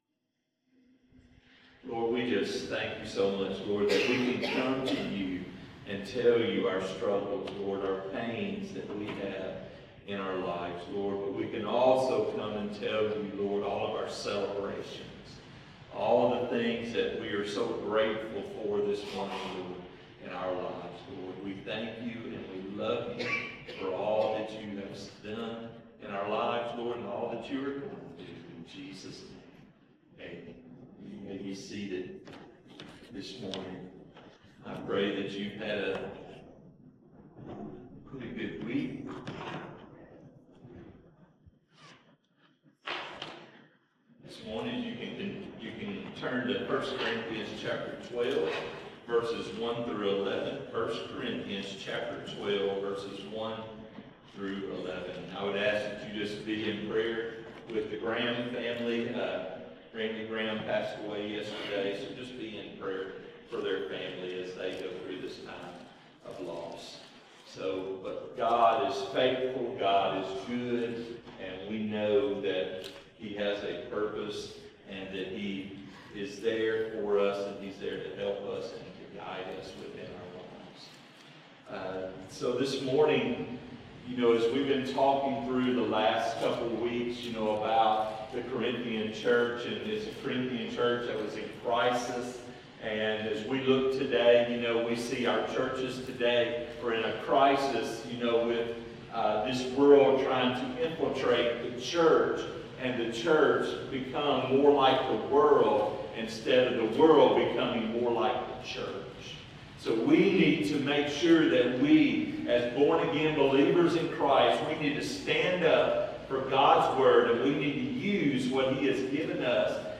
Sermons | First Southern Baptist Church Bearden